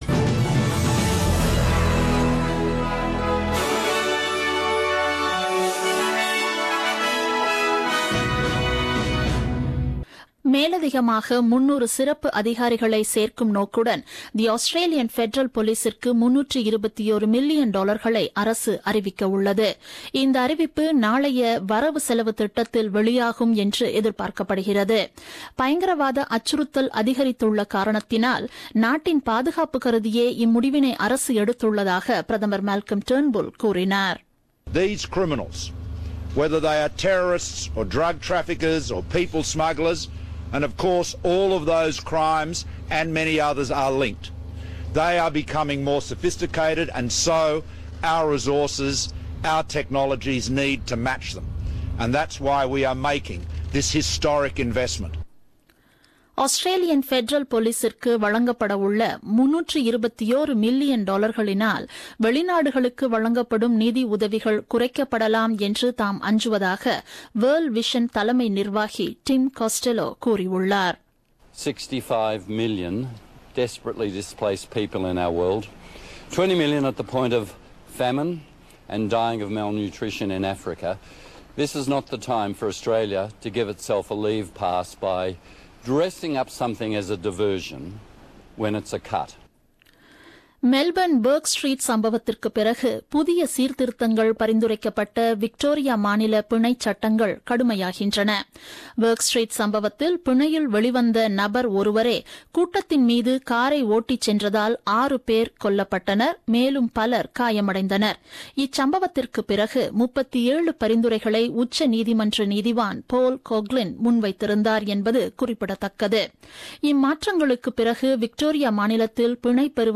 The news bulletin broadcasted on 8th May 2017 at 8pm.